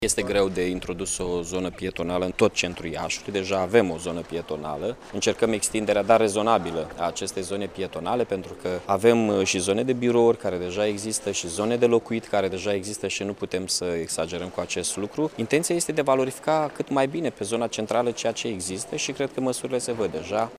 Referindu-se la extinderea pietonalului din centrul Iaşului, edilul a arătat că zona va fi mărită, dar se va ţine cont de clădirile de birouri şi de imobilele de locuit din proximitate: